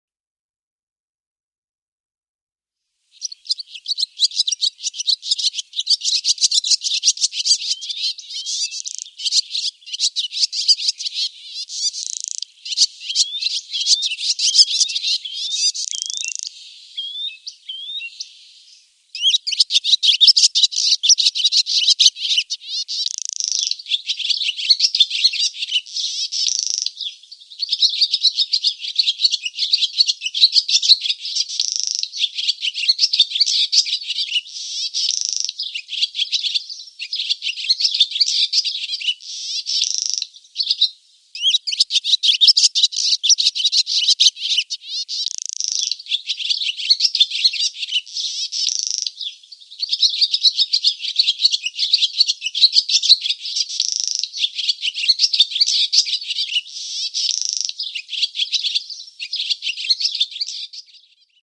Rauch- und Mehlschwalbe
Rauchschwalbe(.mp3) und Mehlschwalbe